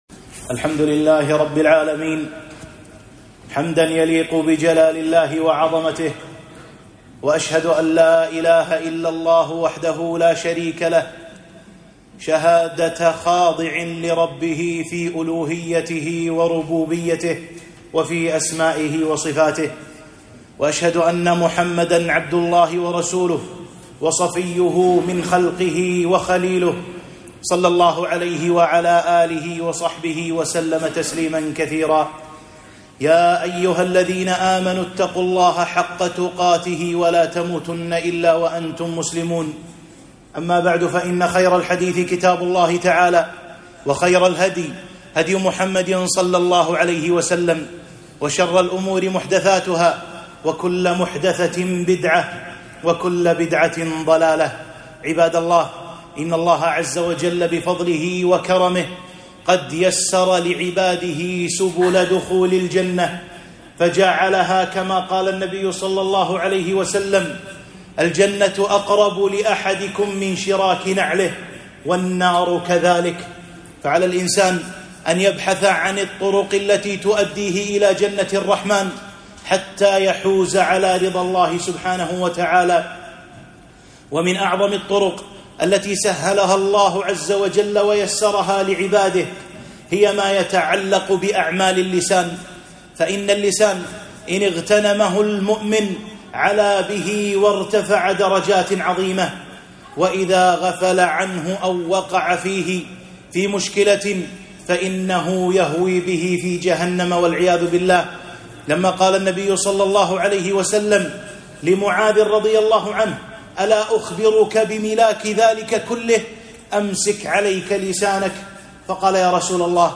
خطبة - ذكر الله تعالى، والاستغفار